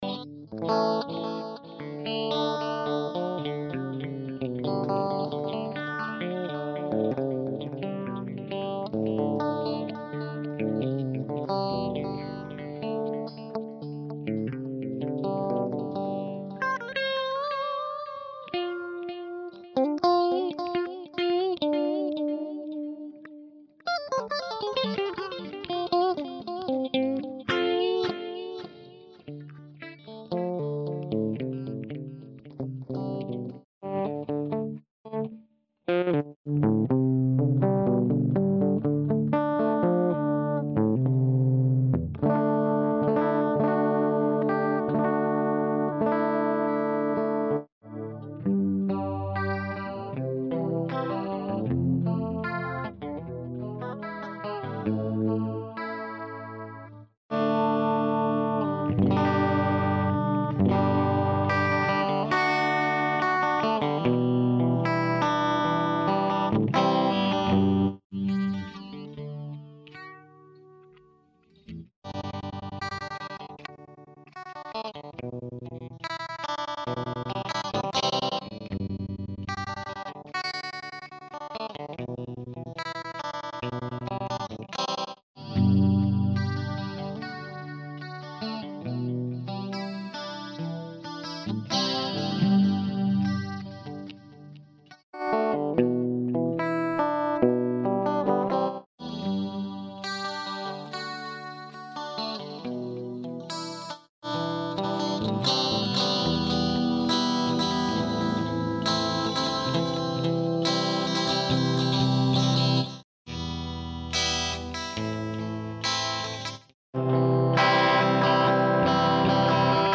效果只能说一般，我们也不指望这种级别的录音机能有多么出色的效果，只是有那么点意思，够做个小样就成了。
点这里下载使用Micro BR内置的效果器录制的吉他这里还有一小段
guitar2.mp3